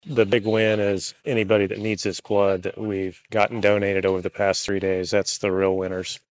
Lyon County Undersheriff John Koelsch says winning the competition feels good, but knowing how many lives will be improved or saved through those donations feels even better.